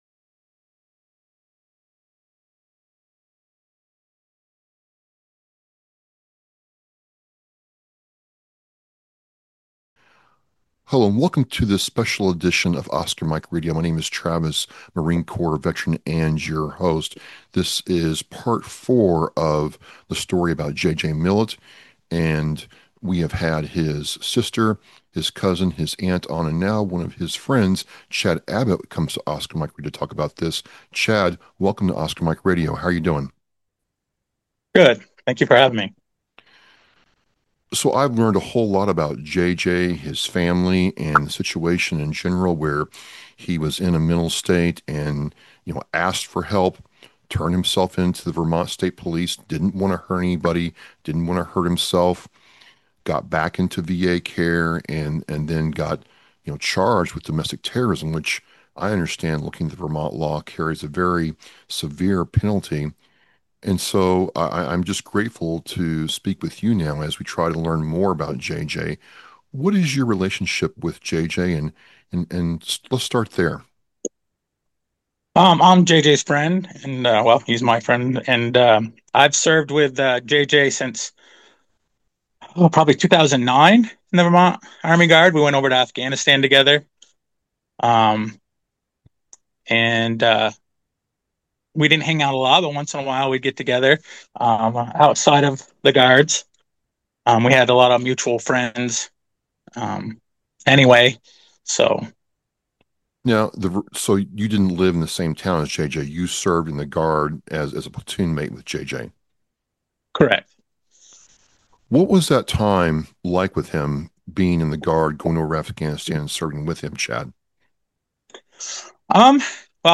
This conversation is about more than one veteran. It’s about how we respond when those who served raise their hand and ask for help.